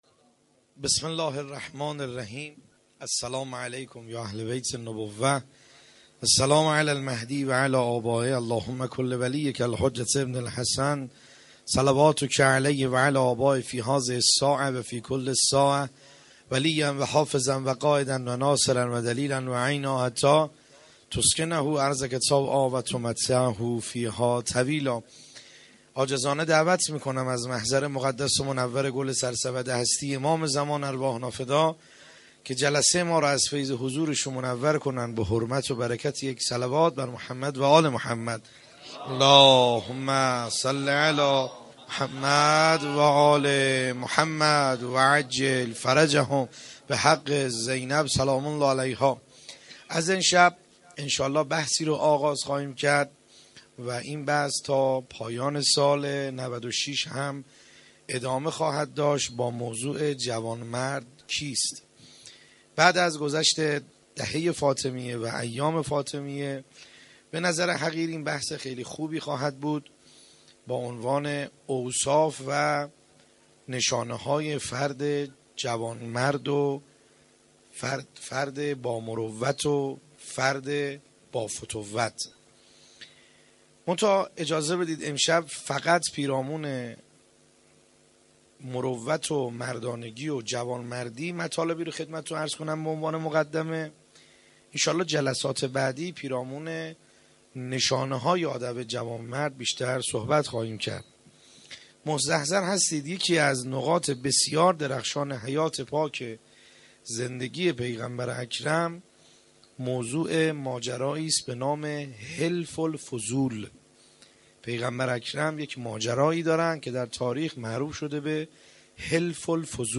خیمه گاه - بیرق معظم محبین حضرت صاحب الزمان(عج) - سخنرانی